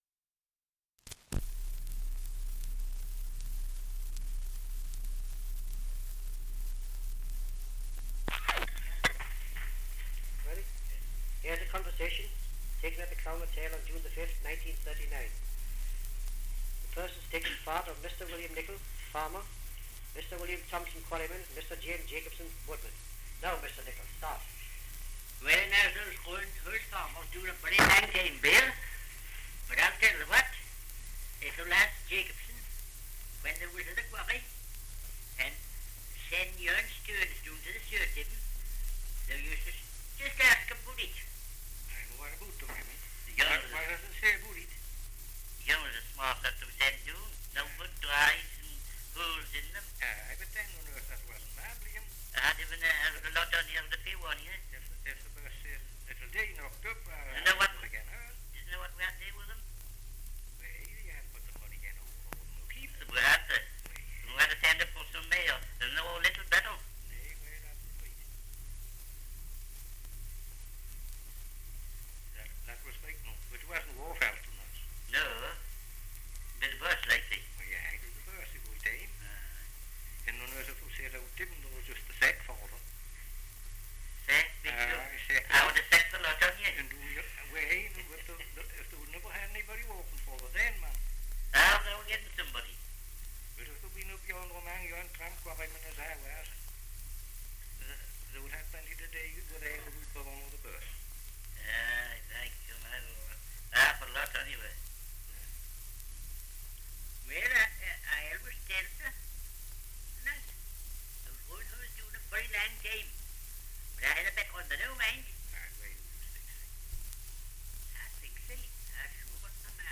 Dialect recording in Humshaugh, Northumberland
78 r.p.m., cellulose nitrate on aluminium